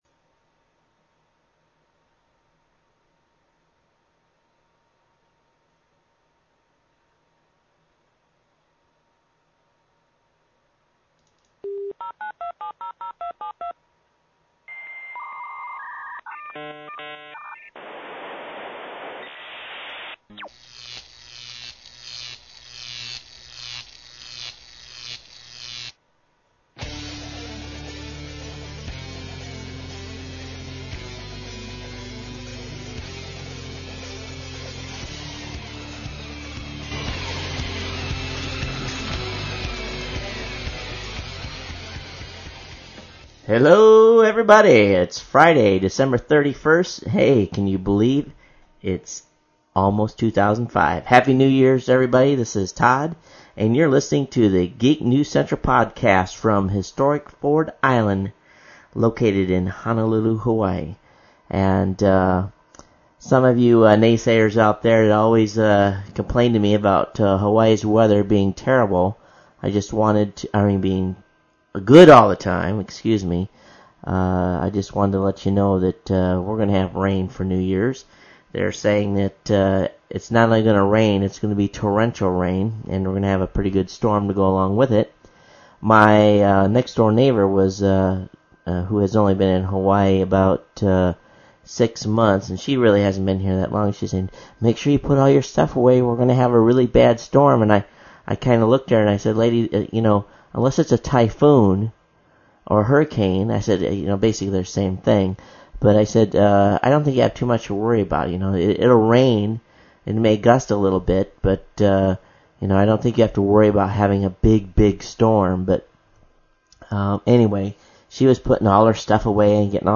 I continue to battle background noise and my audio quality is not where I want it yet, without breaking the bank I got to get my hands around this.